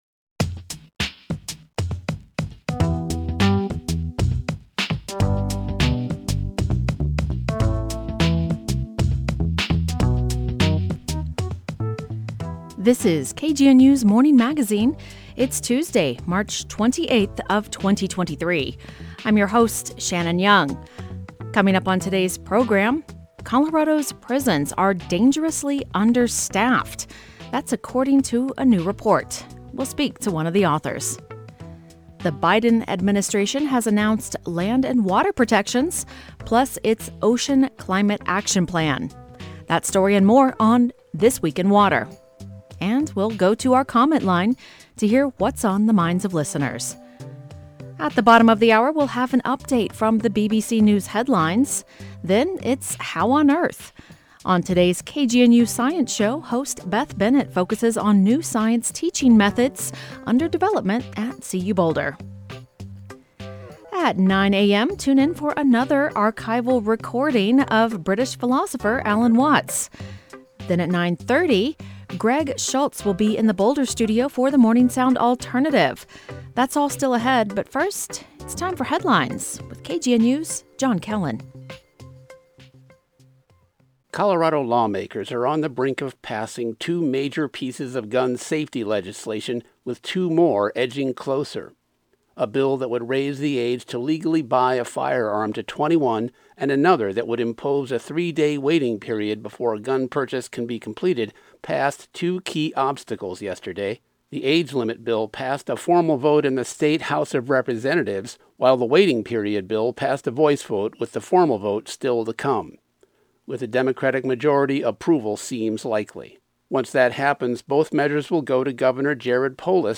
Colorado’s prisons are dangerously understaffed; that’s according to a new report. We’ll speak to one of the authors.
We’ll then go to our comment line to hear what’s on the minds of listeners.